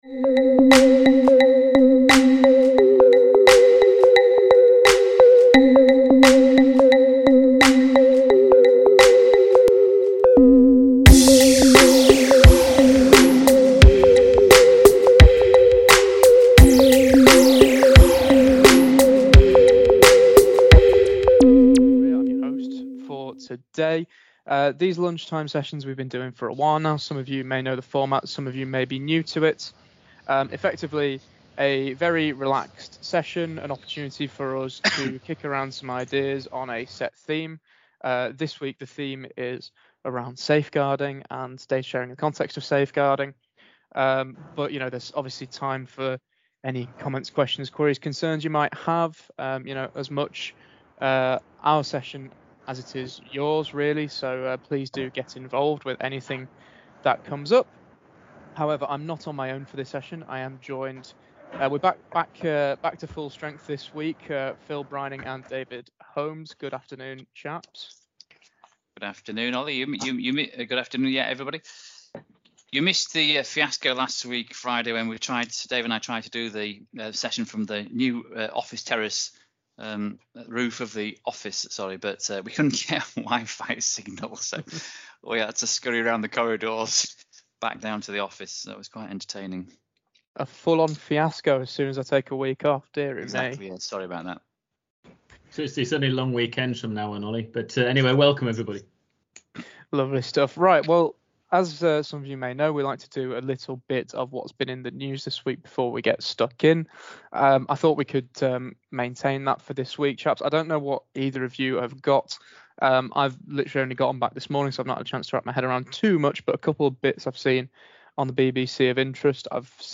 Data Protection People work with several schools, colleges, universities and multi-academy trusts and the common questions asked are about Data Sharing, Safeguarding and Data Protection, we worked with our clients and subscribers to tailor a podcast specifically to the education sector so we can discuss common challenges and share our ideas and insights on finding solutions to those challenges. On the 29th of September, we hosted a webinar on Safeguarding and Data Sharing.